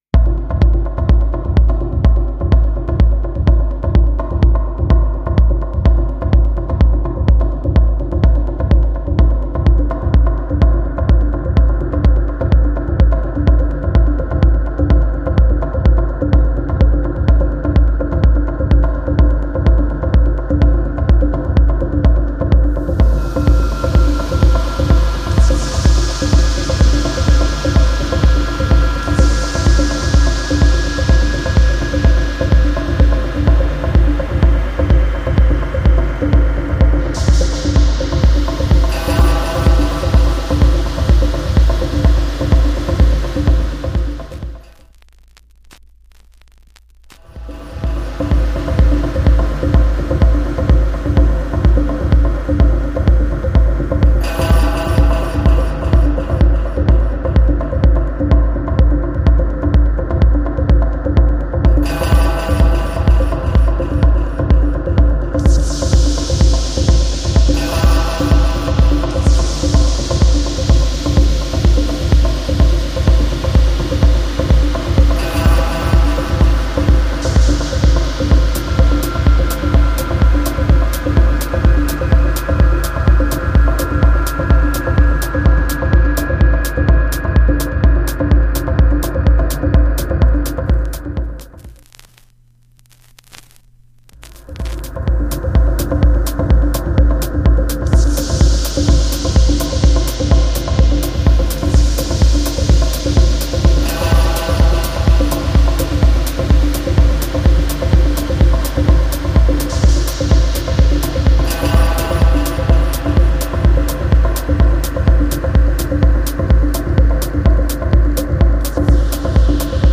reduced atmospheric techno
slow burning hypnotic techno ride